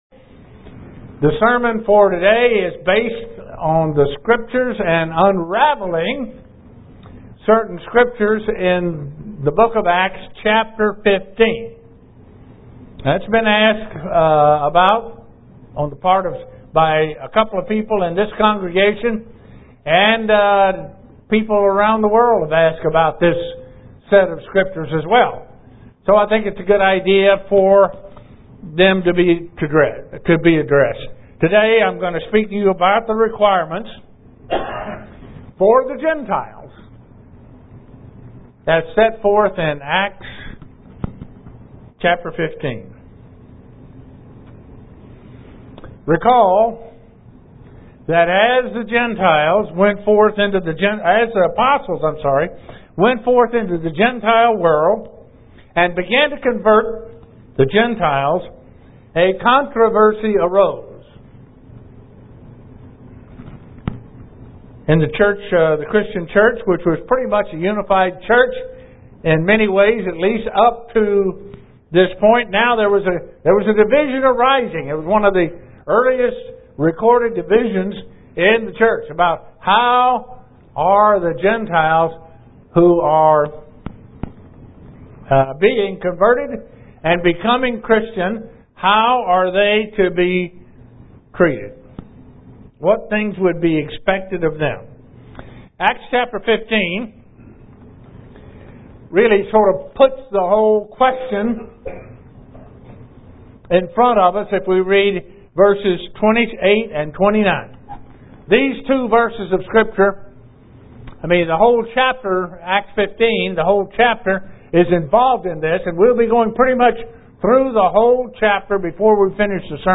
Given in Buffalo, NY
UCG Sermon Studying the bible?